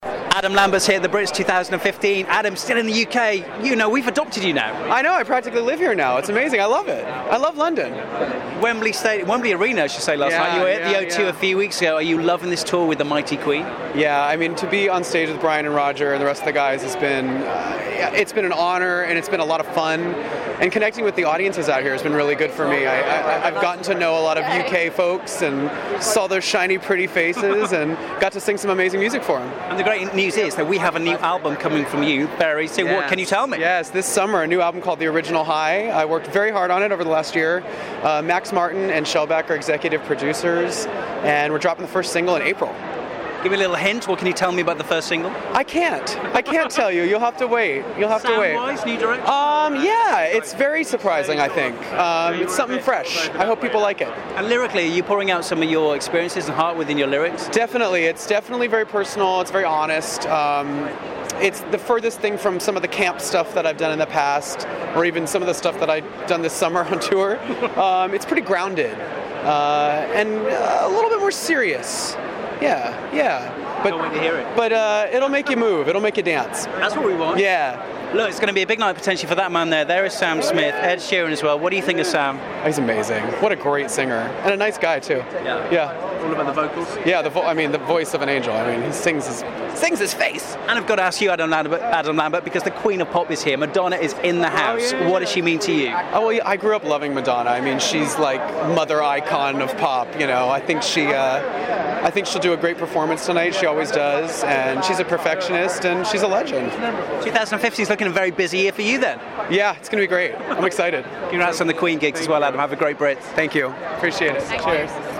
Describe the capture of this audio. at the 2015 Brits